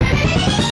jingles-hit_09.ogg